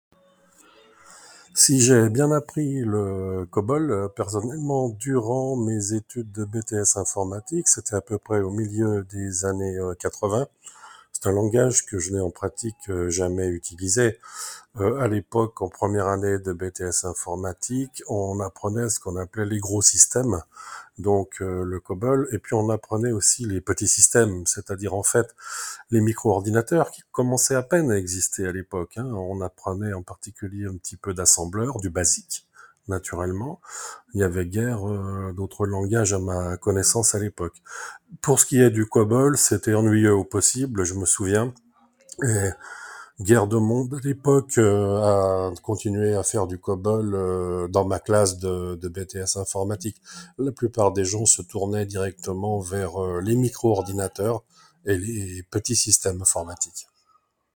Une très intéressante discussion sur l’obsolescence des langages de programmation.